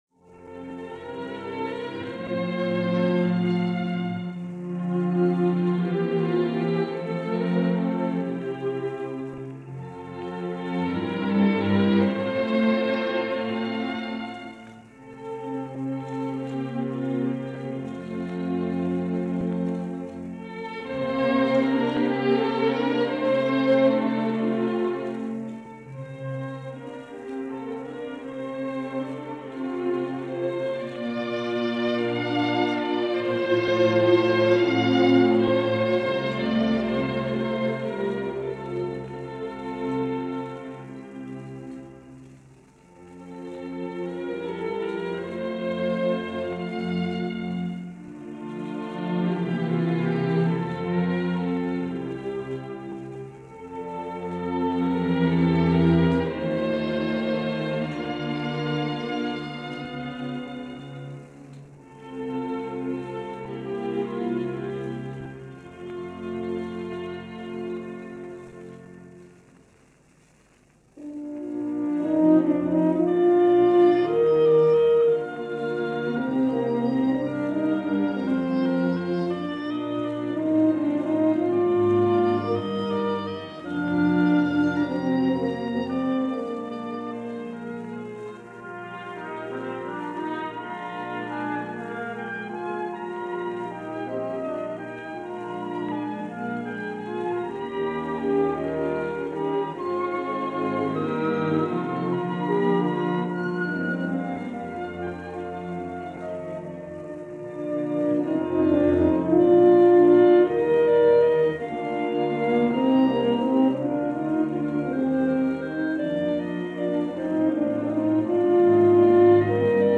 . or click on the link here for Audio Player – Dalcroze – Variations sue La Belle est Suisse – Suisse Romande Orch. – Edmond Appia, cond.
Over to Switzerland this week for a radio session circa 1950 featuring l’Orchestre de la Suisse Romande conducted by Edmond Appia in a performance of Variations sur La Belle est Suisse, by Emile Jaques-Dalcroze.
It’s a lush work which sadly hasn’t been played much (although there is a commercial recording of it featuring the Moscow Symphony). This recording is via a Swiss Radio transcription, recorded between 1950 and 1951.